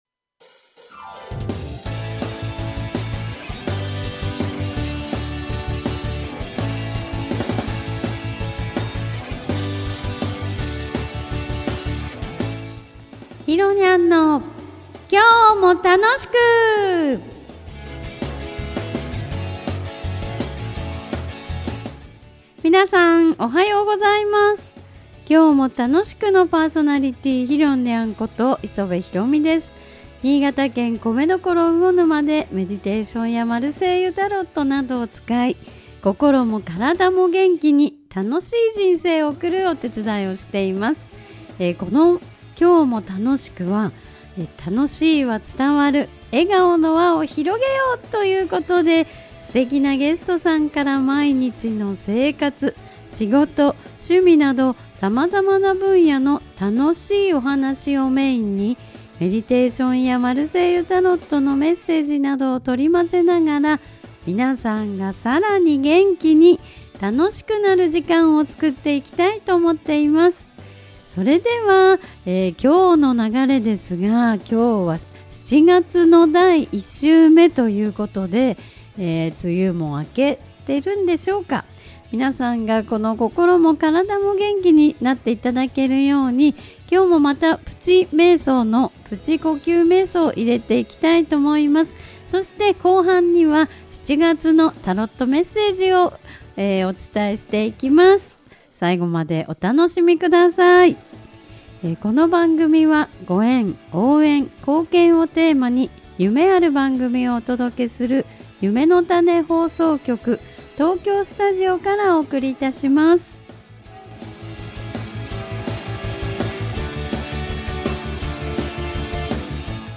安定した話し、さすがです。